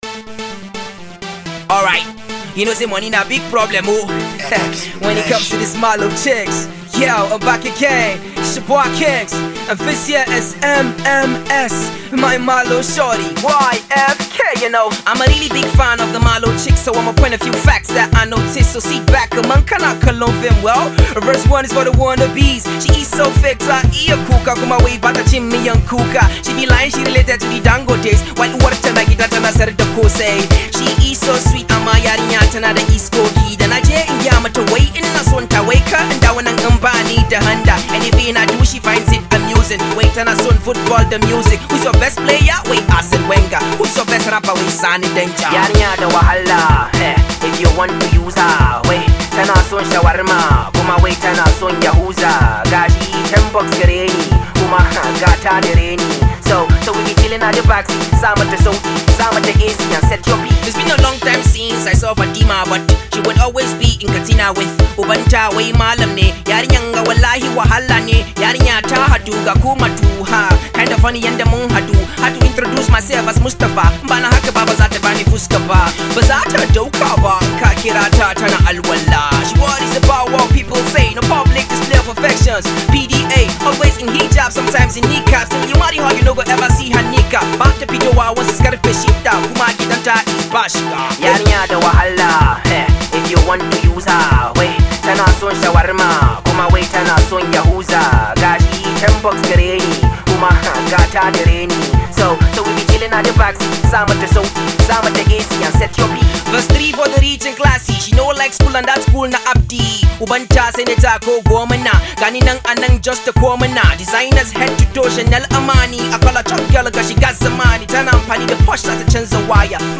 Azonto jam